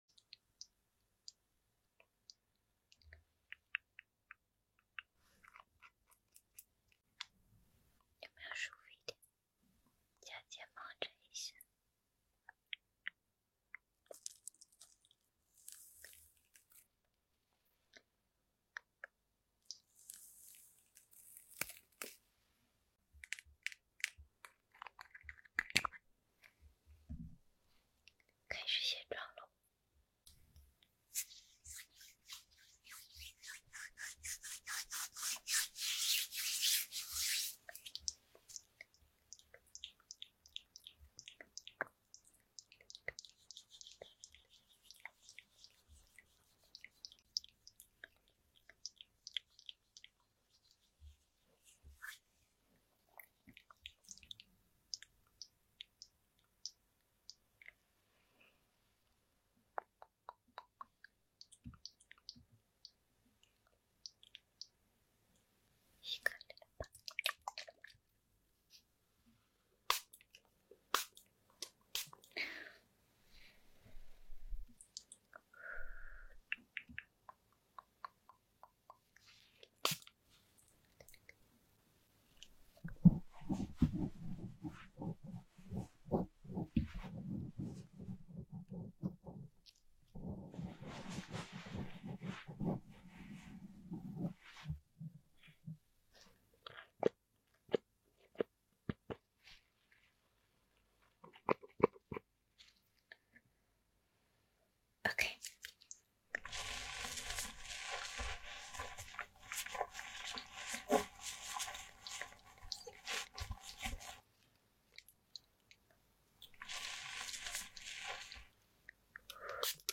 Asmr Clean Your Face Better Sound Effects Free Download